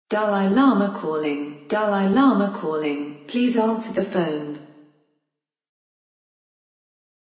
Efectos de sonido